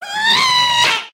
scream2.mp3